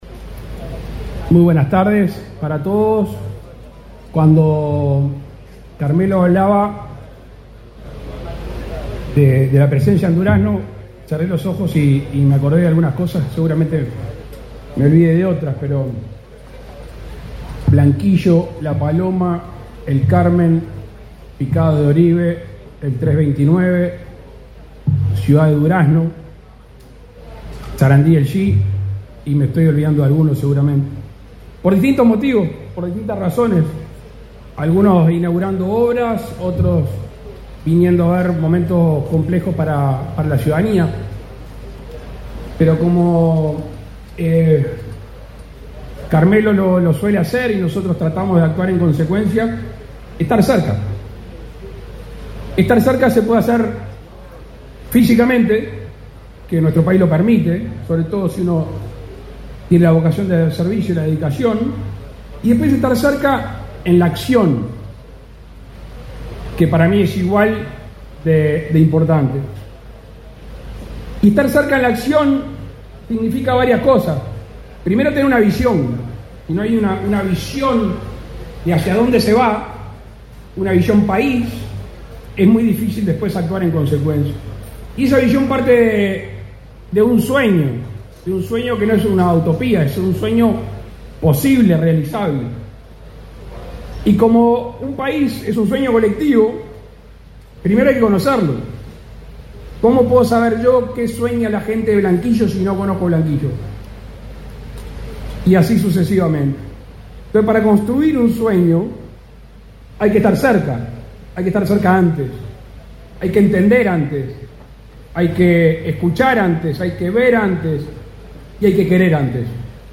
Palabras del presidente de la República, Luis Lacalle Pou
Palabras del presidente de la República, Luis Lacalle Pou 10/02/2025 Compartir Facebook X Copiar enlace WhatsApp LinkedIn Con la presencia del presidente de la República, Luis Lacalle Pou, Aeropuertos Uruguay inauguró, este 10 de febrero, el aeropuerto internacional de Durazno.